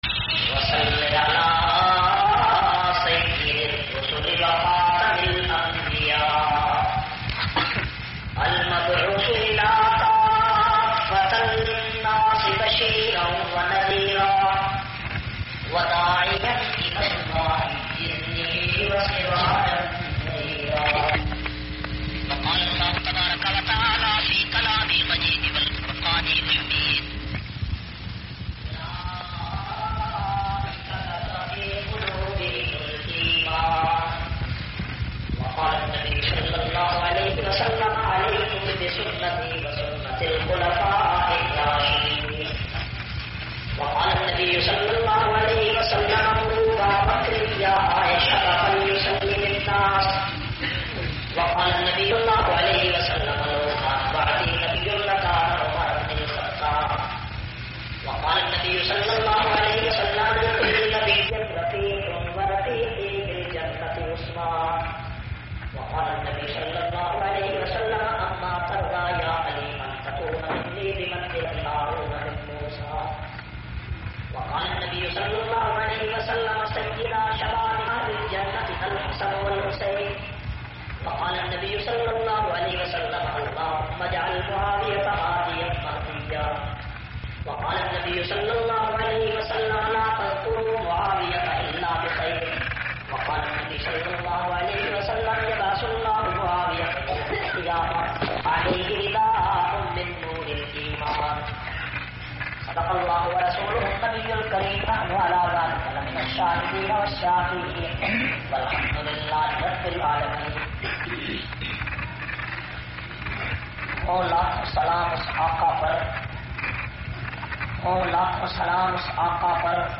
329- Khilafat e Rashida conference Rawalpindi.mp3